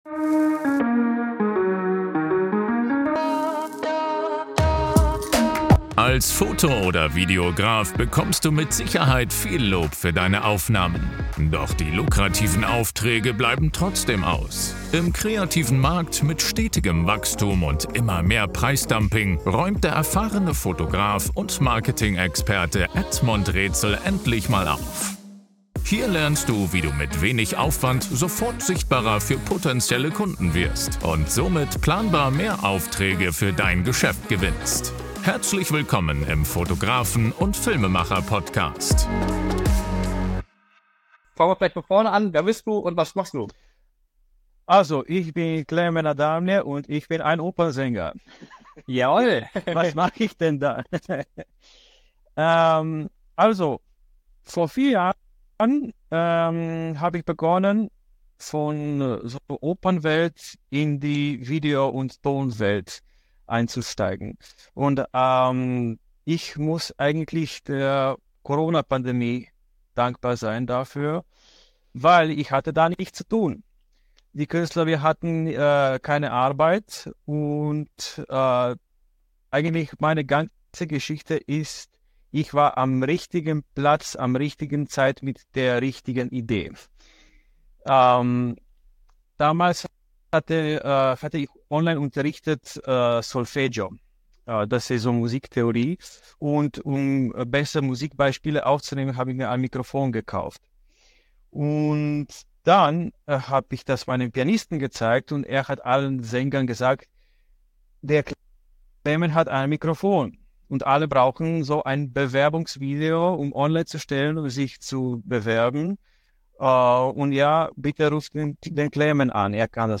Bewegendes Interview